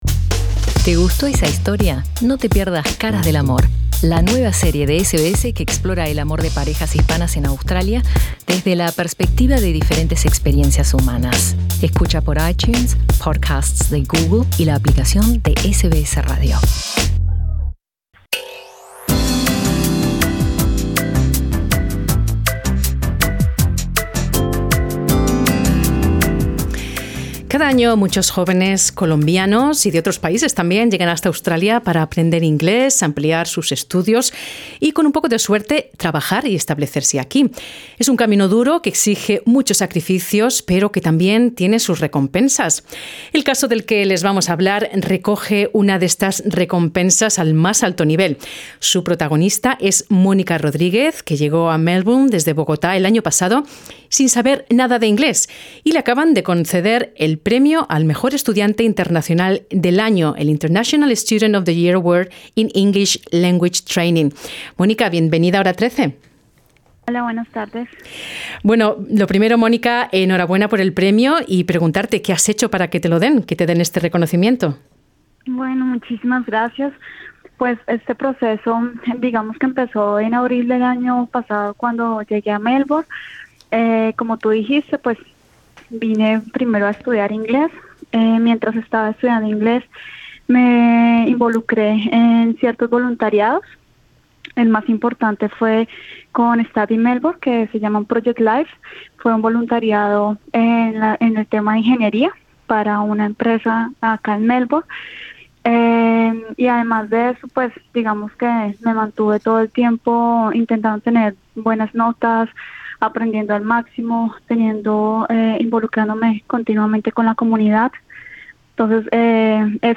Escucha la entrevista y conoce su historia Escúchanos en Radio SBS Spanish 24/7 Puedes escucharnos por Radio Digital, a través de nuestro servicio de streaming en vivo aquí en nuestra página web o mediante nuestra app para celulares.